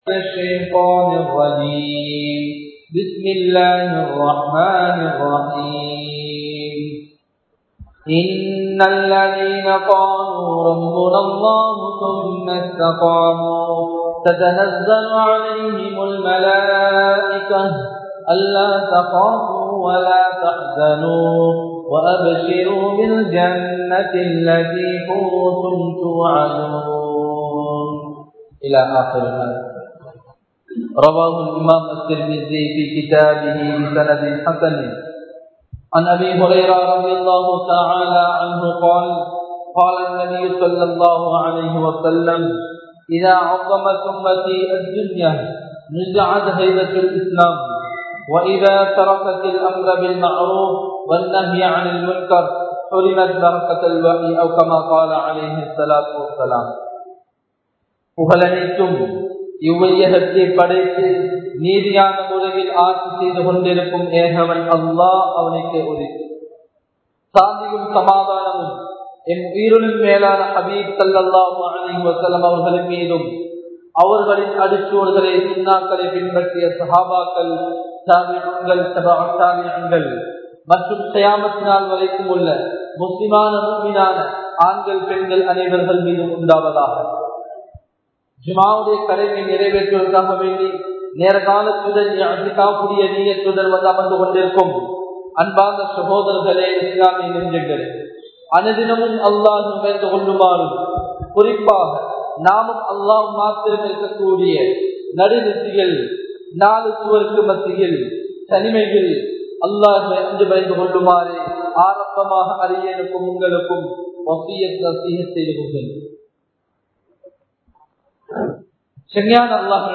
வாலிபத்தின் பெறுமதி | Audio Bayans | All Ceylon Muslim Youth Community | Addalaichenai